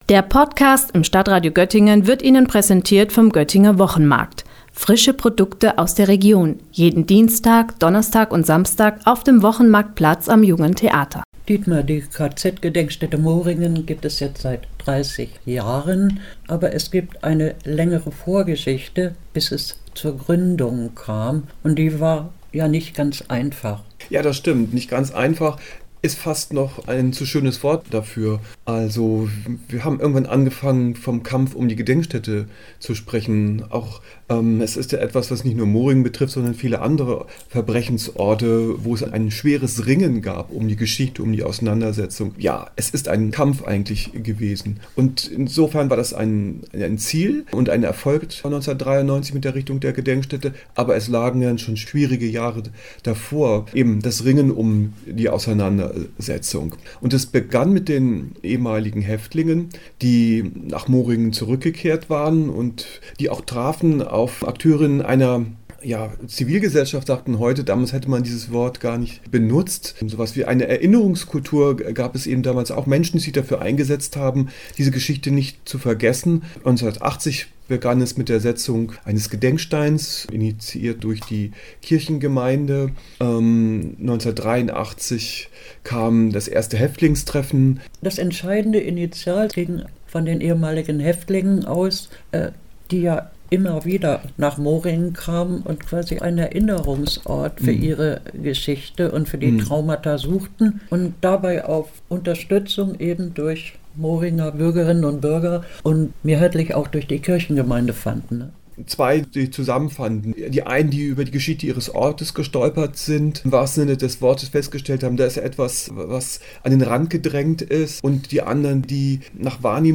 Beiträge > 30 Jahre KZ-Gedenkstätte Moringen – Gespräch